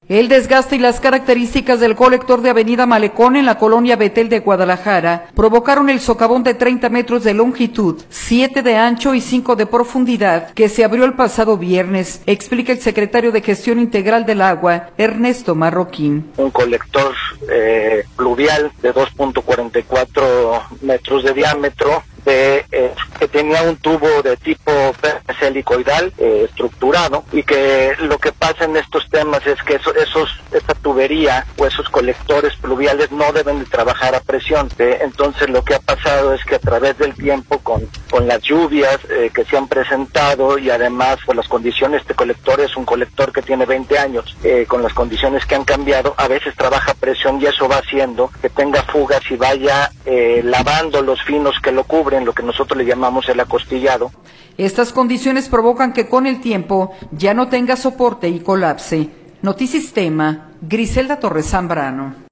El desgaste y las características del colector de avenida Malecón en la colonia Bethel de Guadalajara, provocaron el socavón de 30 metros de longitud, siete de ancho y cinco de profundidad que se abrió el pasado viernes, explica el secretario de Gestión Integral del Agua, Ernesto Marroquín.